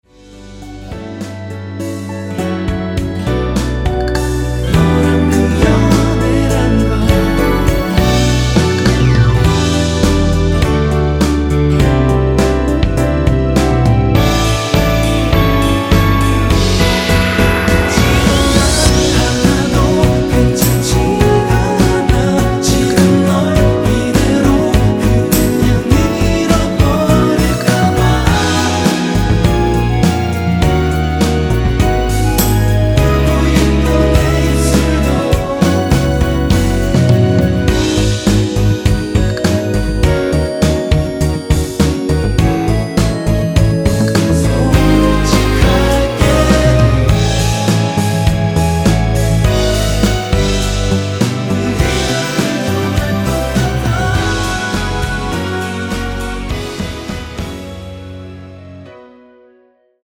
원키 멜로디와 코러스 포함된 MR입니다.(미리듣기 확인)
앞부분30초, 뒷부분30초씩 편집해서 올려 드리고 있습니다.
(멜로디 MR)은 가이드 멜로디가 포함된 MR 입니다.